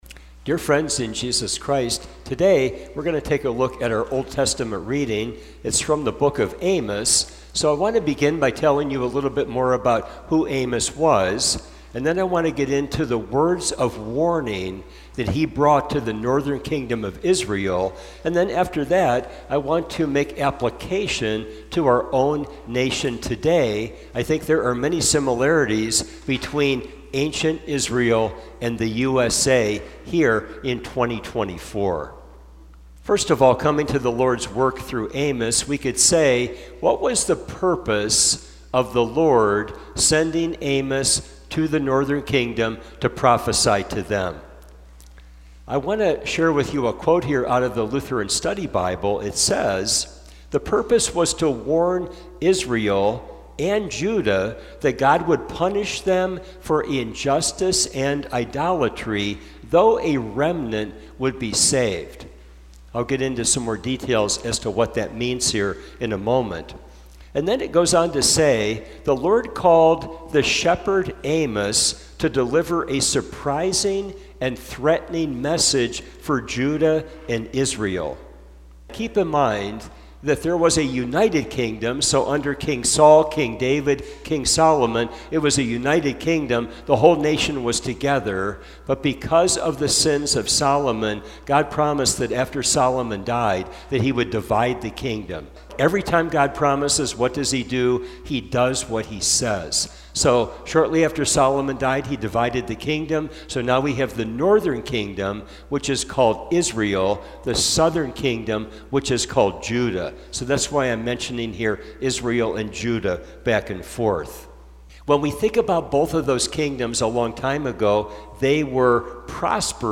This sermon compares the northern kingdom of Israel with the United States of America; let us see the similarities and repent!